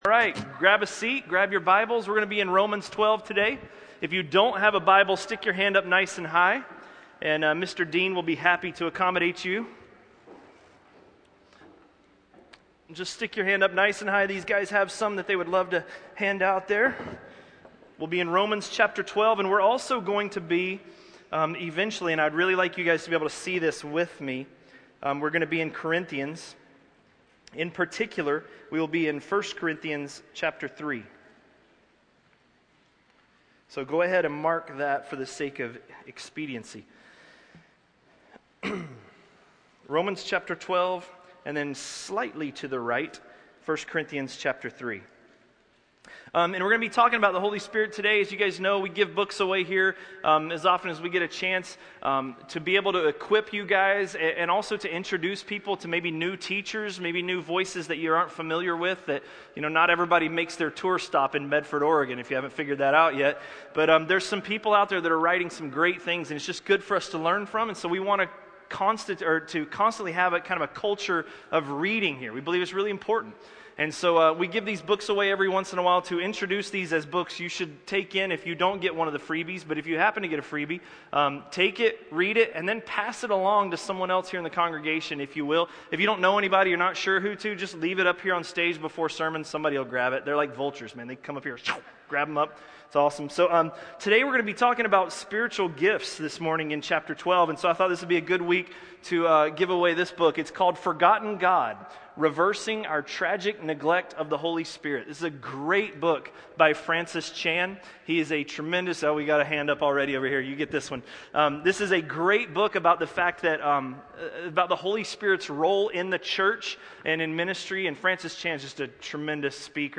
A message from the series "Romans." Romans 12:3–12:8